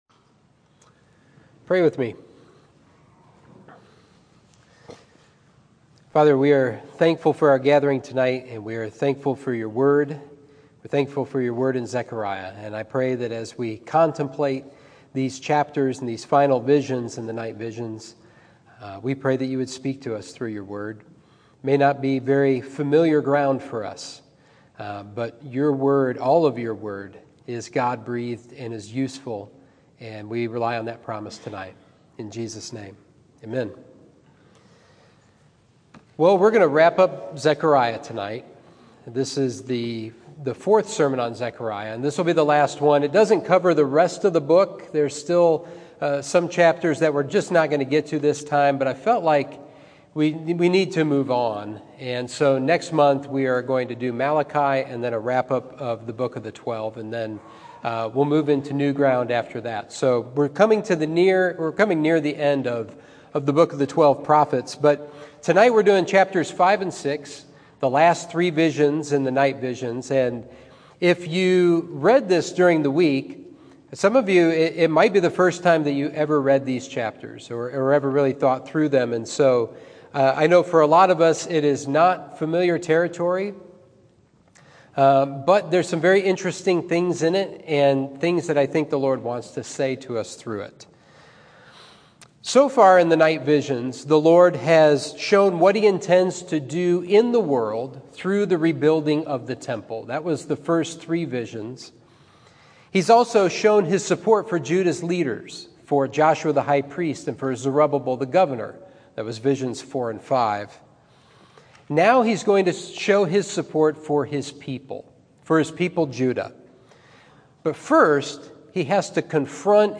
Sermon 1/23: Zechariah: Choose Your Crown